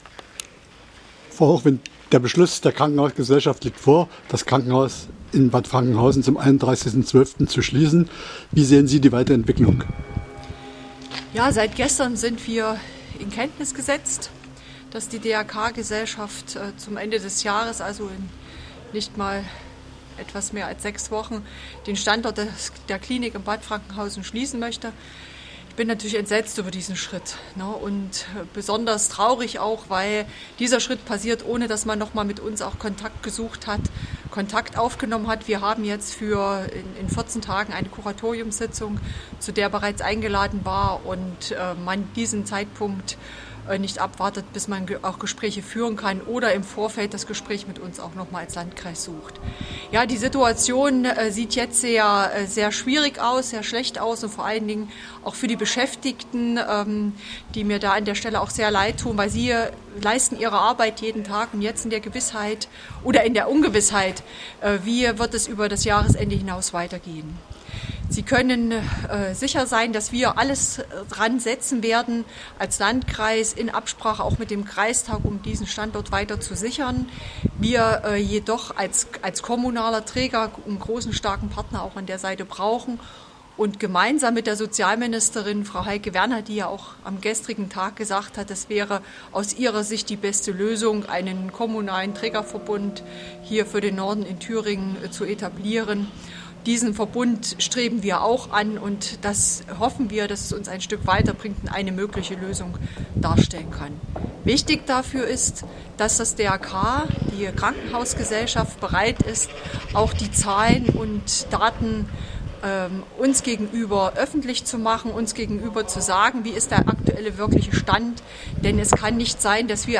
Am Rande einer Veranstaltung am Klosterturm von Göllingen gab Landrätin Antje Hochwind gegenüber kn dieses Statement ab: